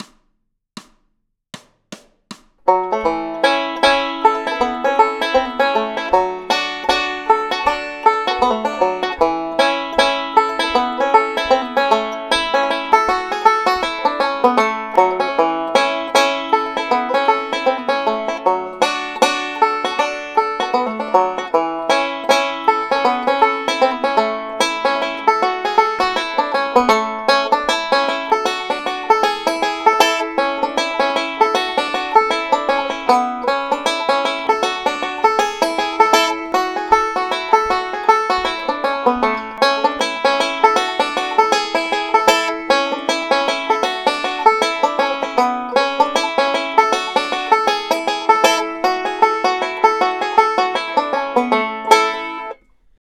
my version with some melodic style